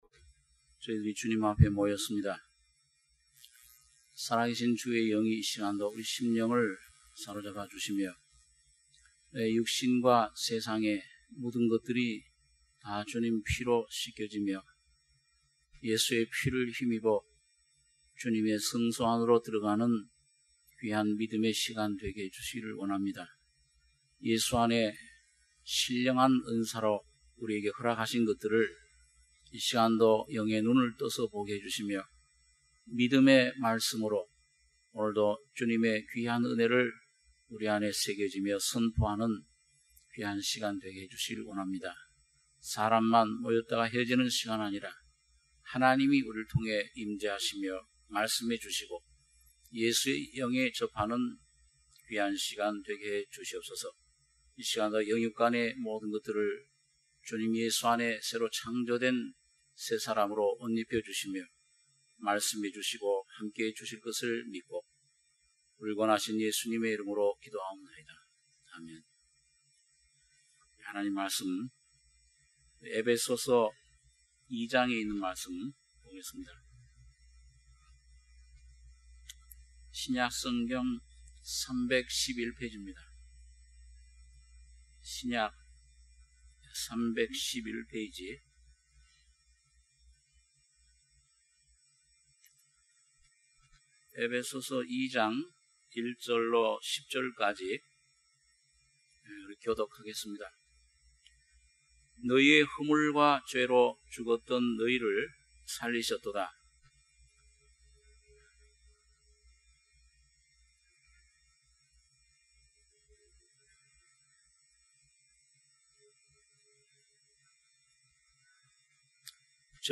주일예배 - 에베소서 2장 1-10절(주일오후)